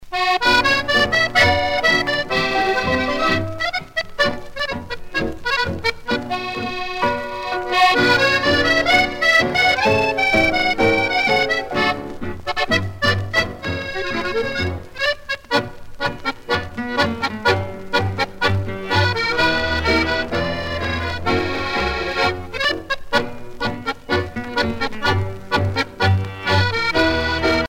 danse : tango musette
Pièce musicale éditée